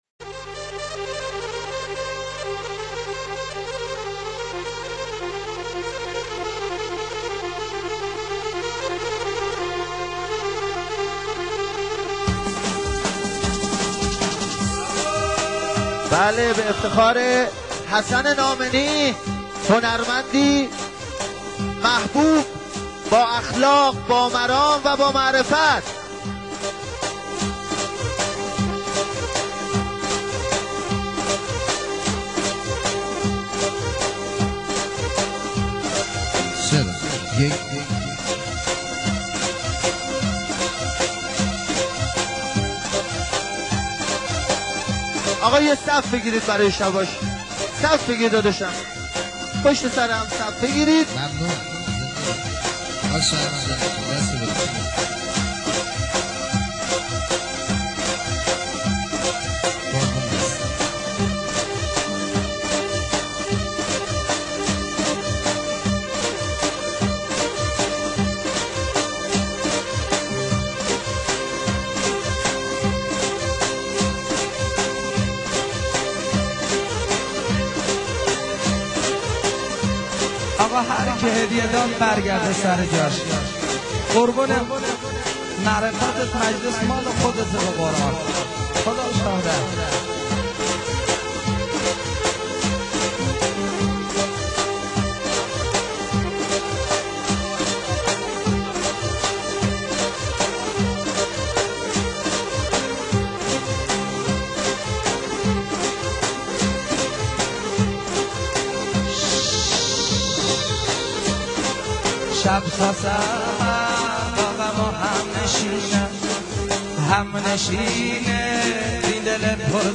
موسیقی کرمانجی